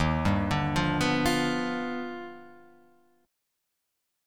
Eb+M9 Chord